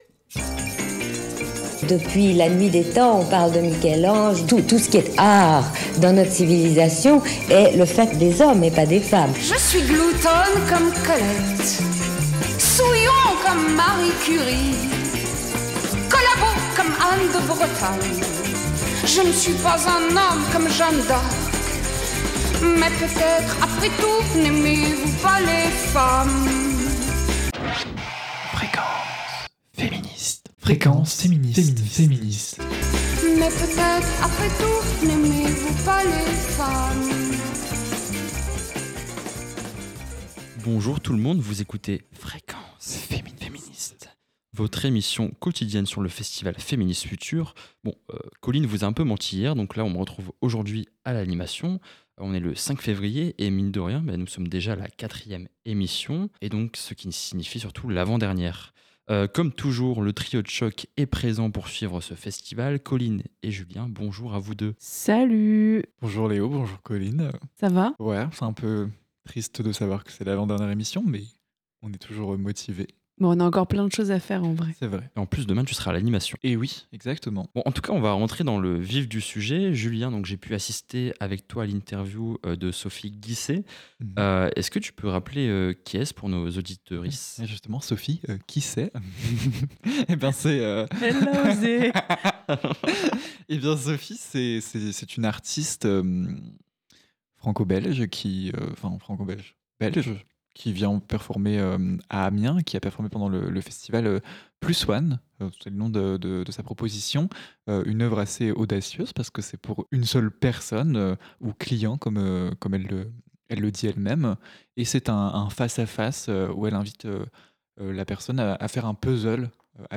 Au programme donc : interviews, chroniques, suggestions littéraires … vous attendent pour profiter avec nous de ces moments de partage et de rencontre.